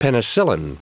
Prononciation du mot penicillin en anglais (fichier audio)
Prononciation du mot : penicillin
penicillin.wav